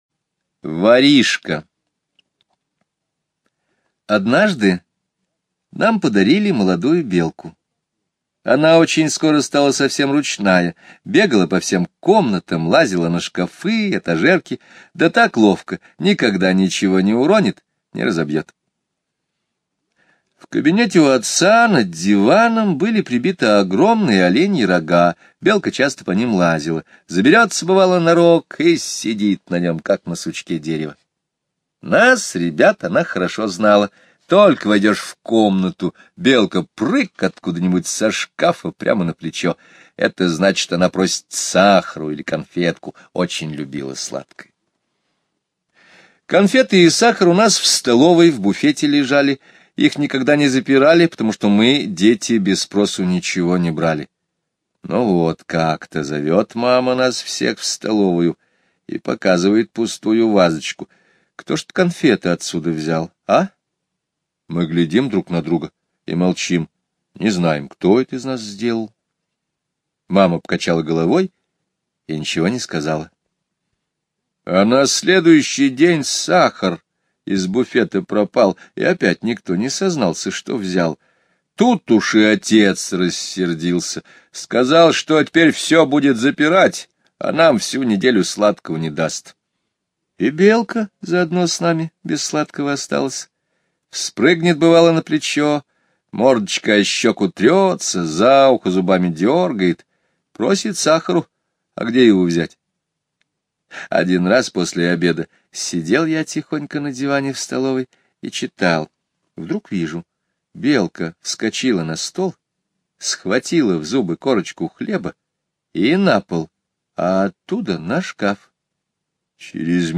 Слушайте Воришка - аудио рассказ Скребицкого Г. Рассказ про домашнюю белку, которая осенью повадилась воровать сахар и конфеты из шкафа.